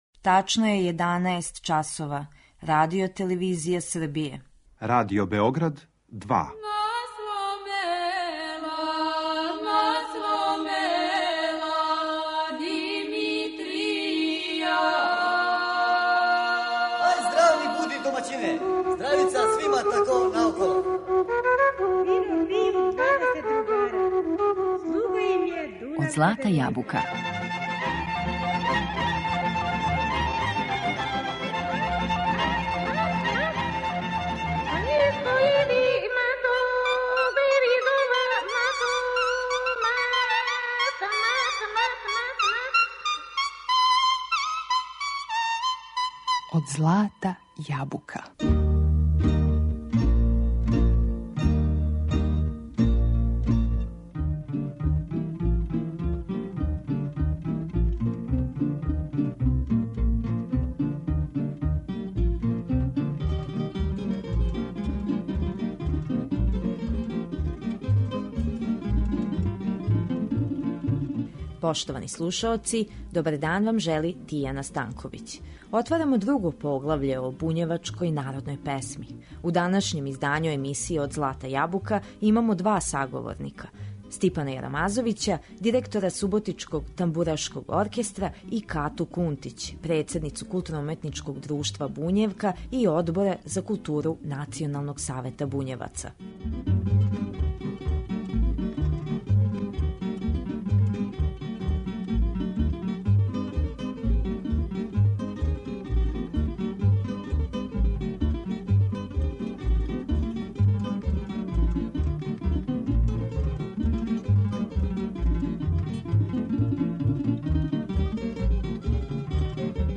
У данашњем издању емисије Од злата јабука имамо два саговорника